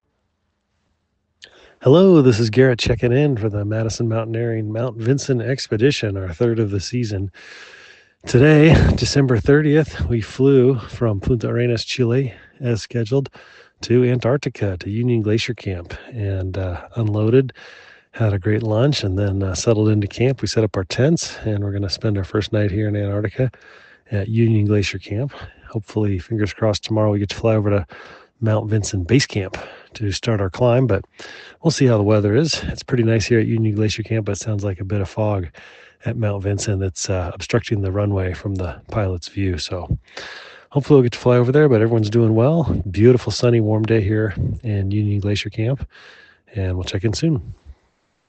The team met in Punta Arenas and has since landed on the frozen continent where they await their flight to Mount Vinson Base Camp.